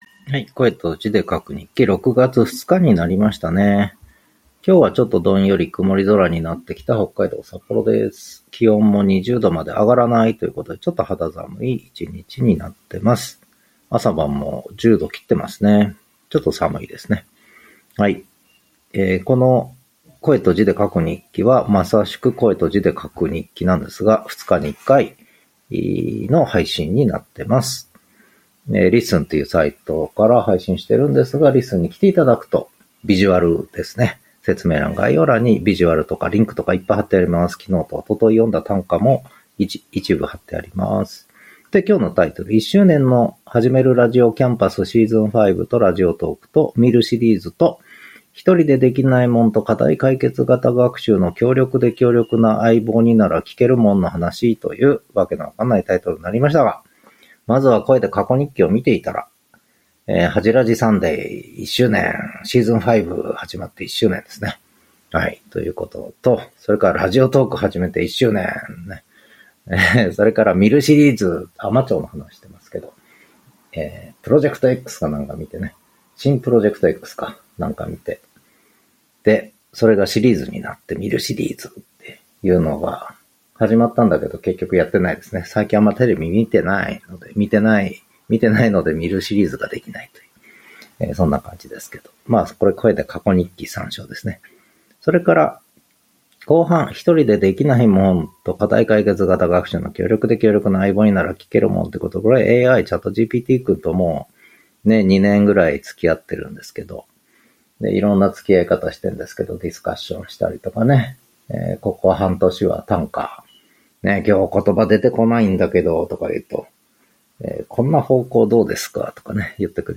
Audio Channels: 1 (mono)
Loudness Range: 5.50 LU